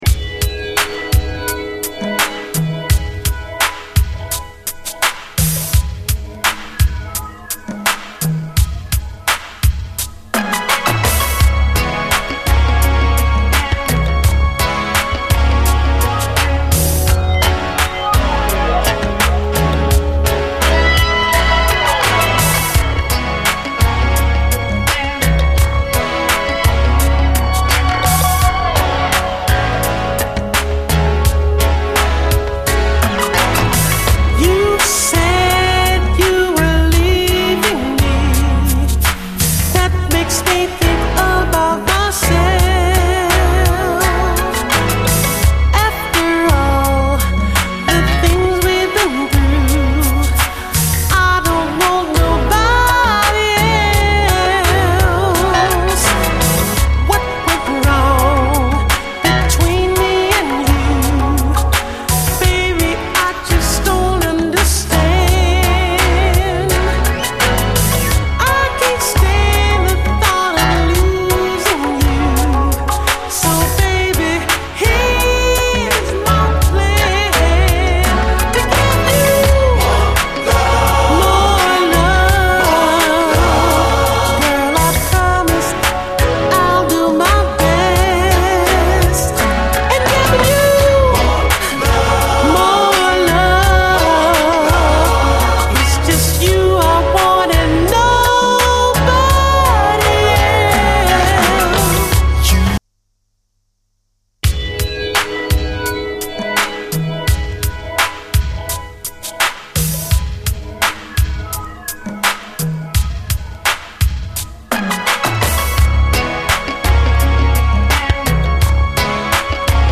SOUL, 70's～ SOUL, DISCO
ギラギラと鬼メロウなシンセ・サウンドに惚れる、シカゴ産80’Sメロウ・シンセ・ファンク〜モダン・ソウル！